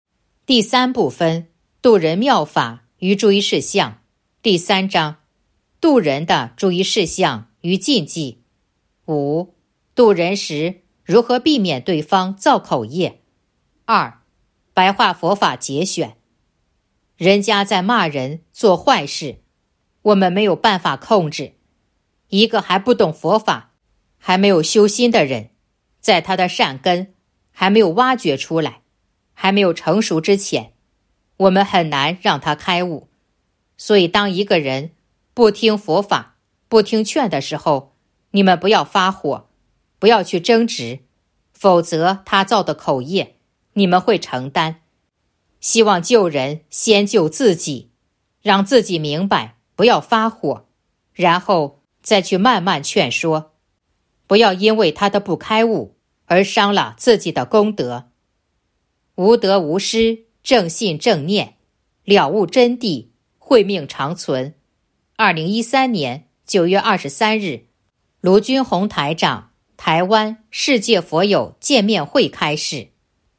059.2. 白话佛法节选《弘法度人手册》【有声书】